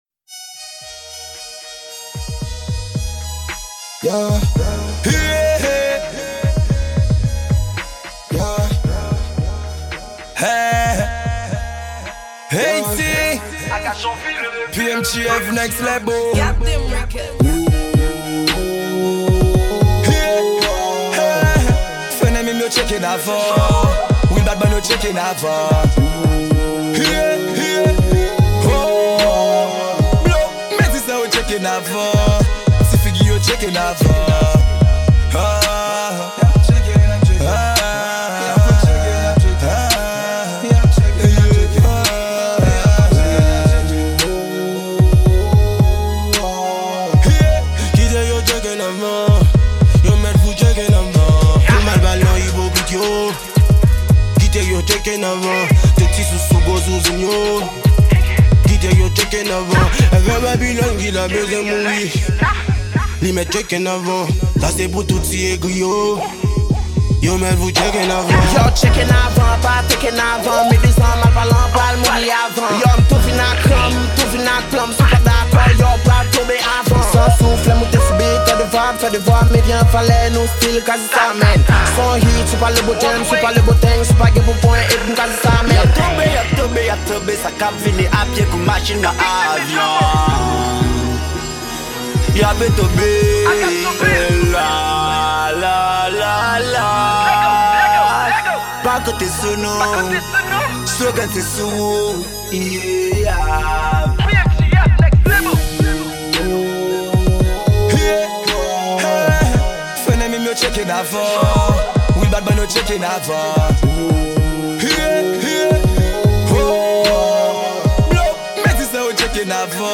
Genre: RAO.